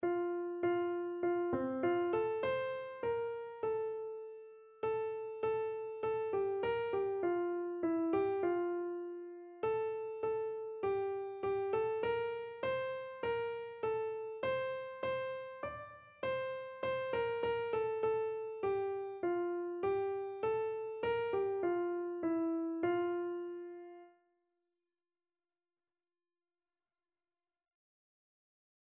Keyboard version
Free Sheet music for Keyboard (Melody and Chords)
4/4 (View more 4/4 Music)
Classical (View more Classical Keyboard Music)